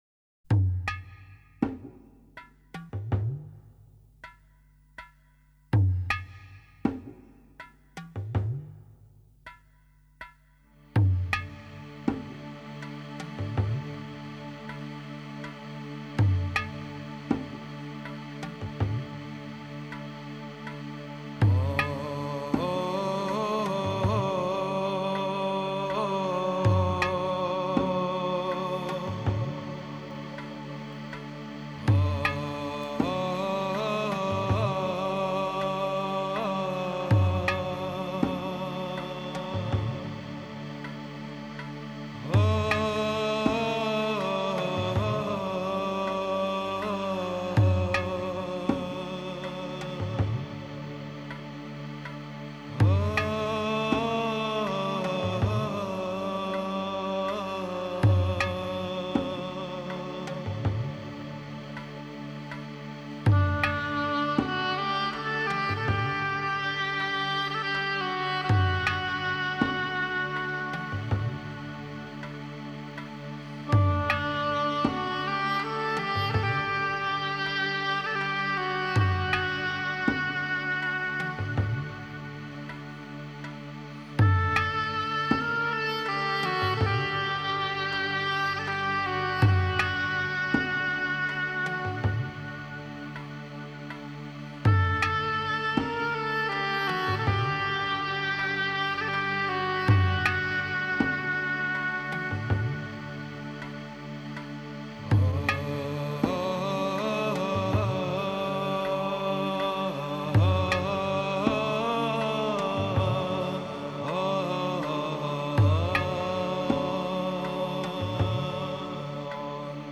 دانلود دکلمه در انتهای هر سفر با صدای حسین پناهی با متن
گوینده :   [حسین پناهی]